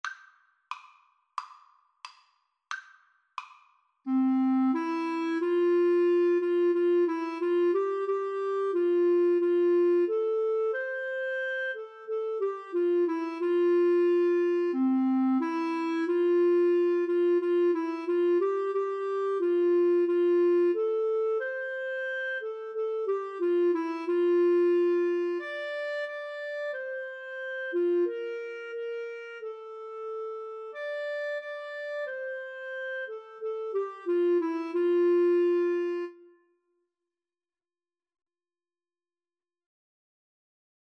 Play (or use space bar on your keyboard) Pause Music Playalong - Player 1 Accompaniment reset tempo print settings full screen
4/4 (View more 4/4 Music)
F major (Sounding Pitch) (View more F major Music for Clarinet-Viola Duet )